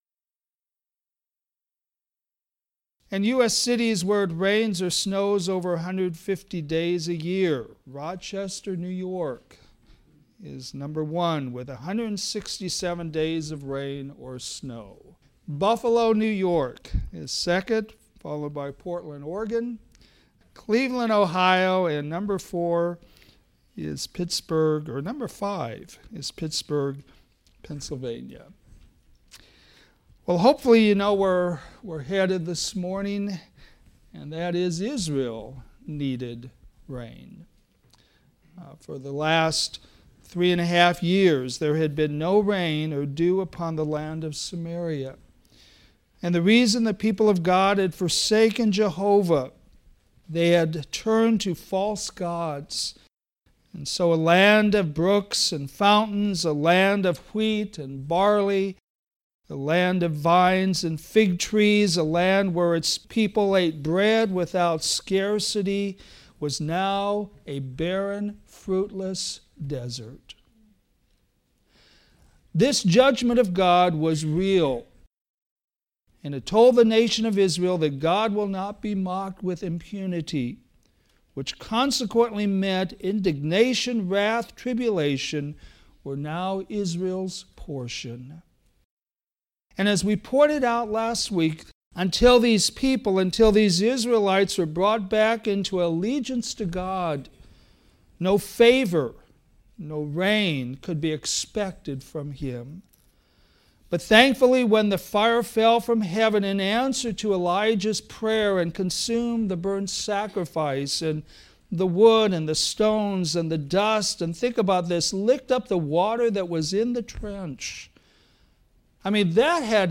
Sermons
Sunday AM